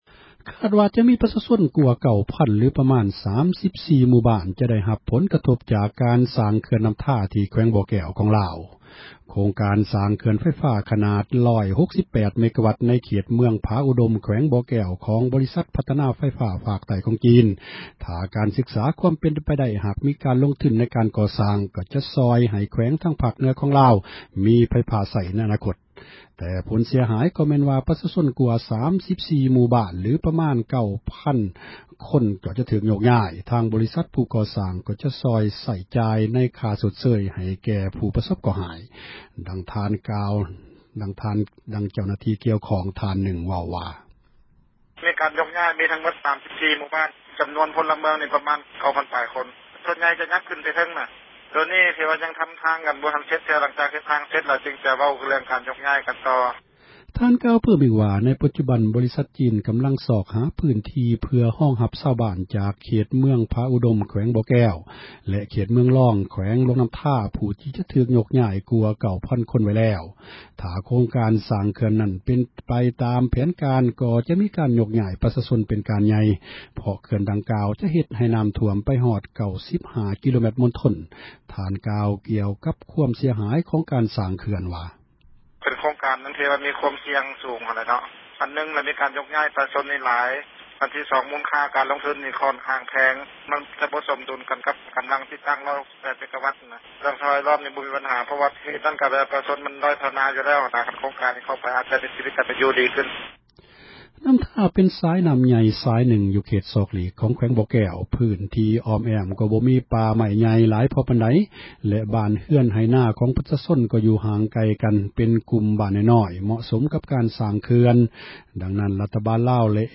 ສຽງເຄື່ອນນ້ຳທາ